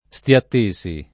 Stiattesi [ S t L att %S i ] cogn.